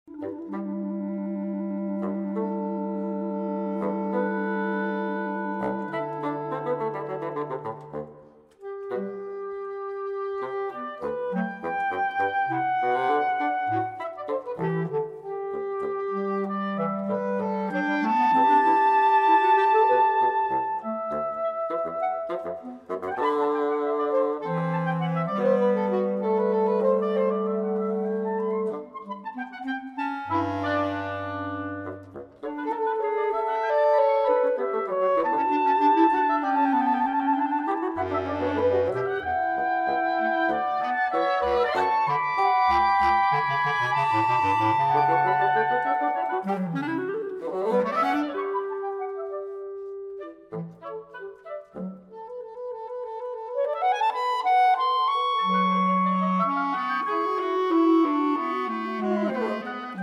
Reed Quintet